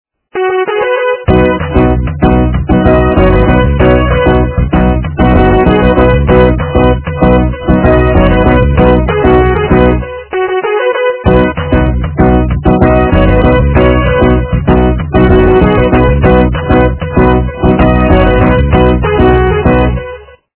русская эстрада
полифоническую мелодию